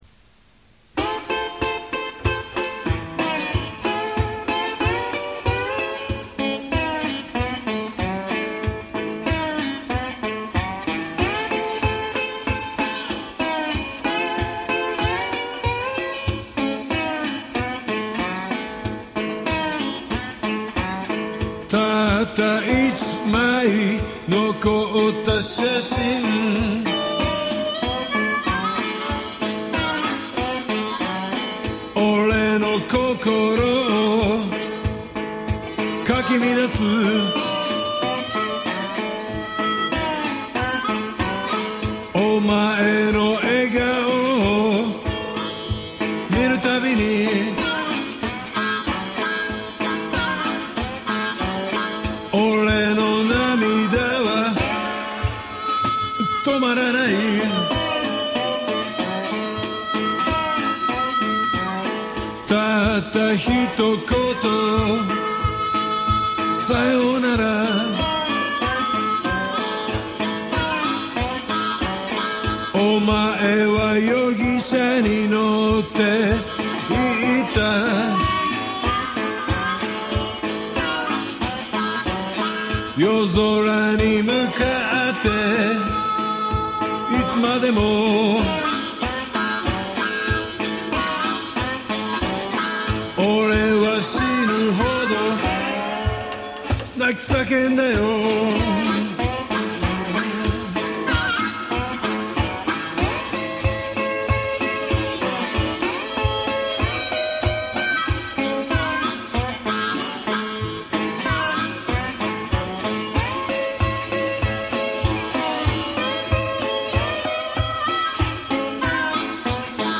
Ｌｅｘｉｃｏｎリバーブで奥行きを、思いっきりつけてみた。
ガレージでの演奏っぽくしたかったので・・・。
ガヤガヤした騒音やグラスのカチャカチャした音をいれたら、かなりライブっぽくなったと思うけど・・・。